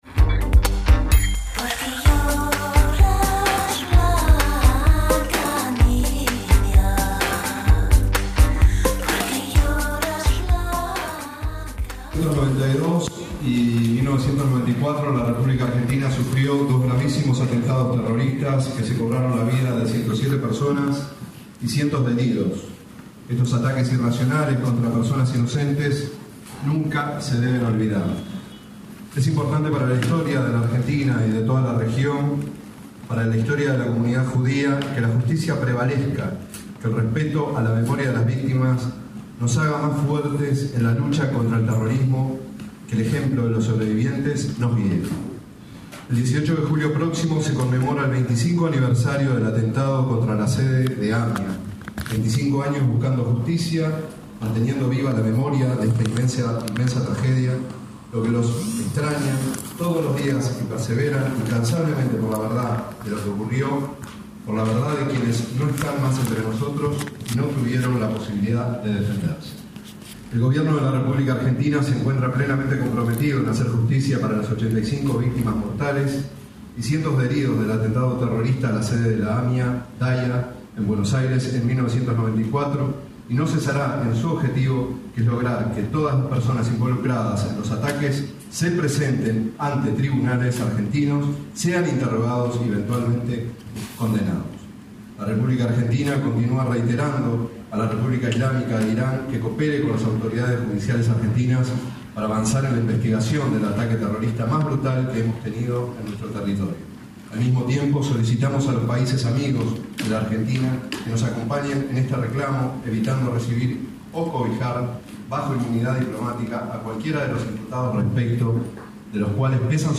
Acto conmemorativo del 25º Aniversario del atentado a la Asociación Mutual Israelita Argentina (AMIA), en la Embajada argentina en Madrid